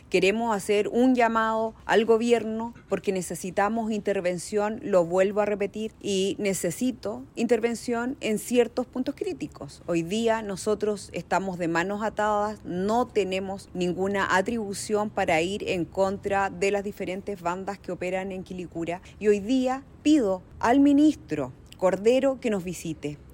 Quien también se refirió a este homicidio fue la alcaldesa de Quilicura, Paulina Bobadilla. Ella hizo un llamado a la intervención, incluso militar, en los sectores más problemáticos de la comuna.